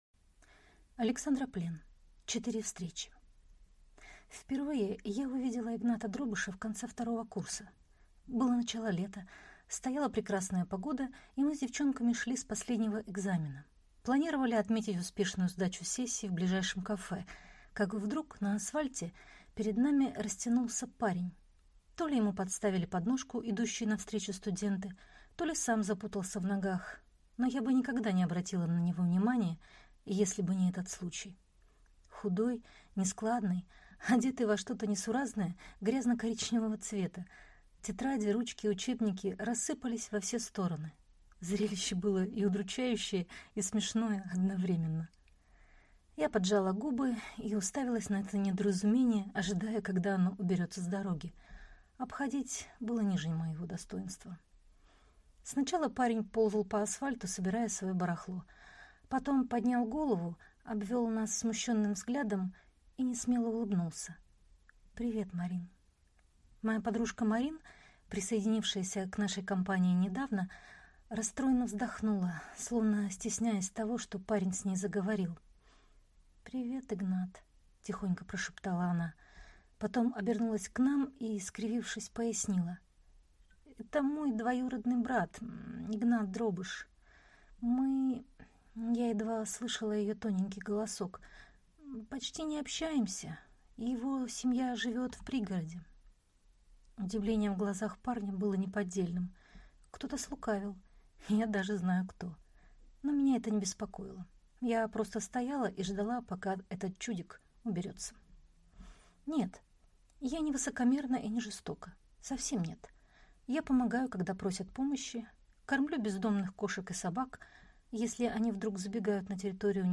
Аудиокнига «Четыре встречи».